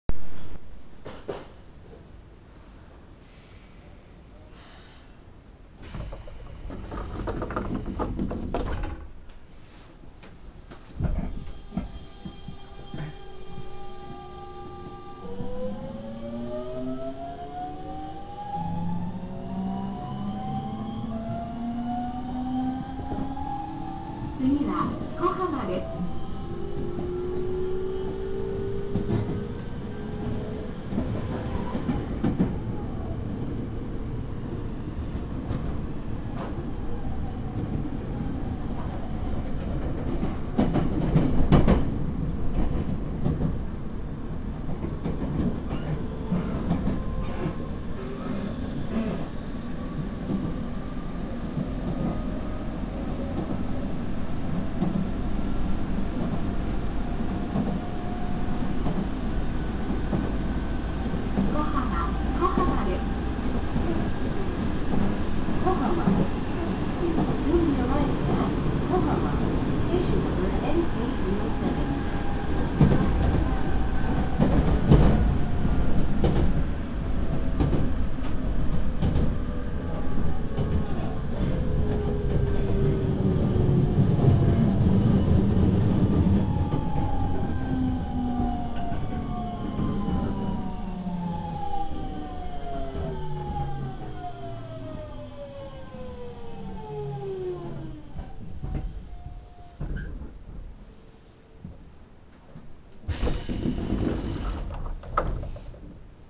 〜車両の音〜
・1000系 走行音
【南海本線】岸里玉出→粉浜（1分41秒：578KB）
日立後期GTOで非常に重厚な音になります。